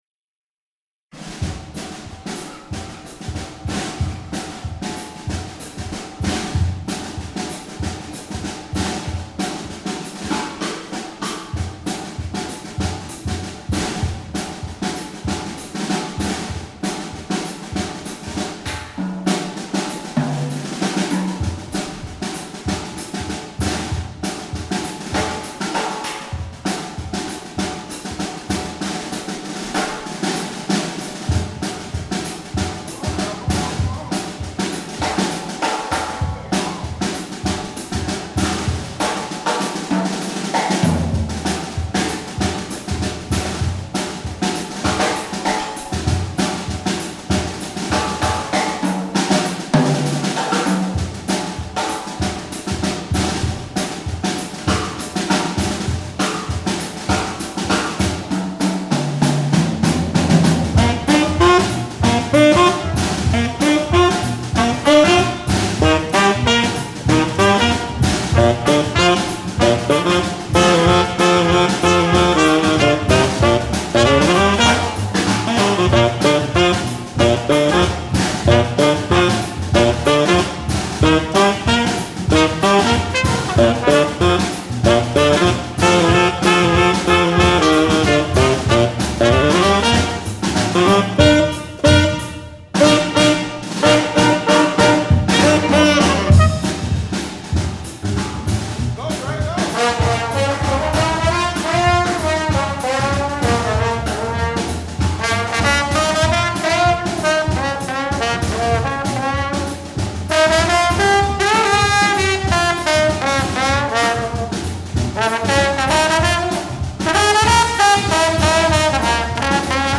performed live
Tenor
Drums
Bass
Trumpet
Trombone
Violin
Vocals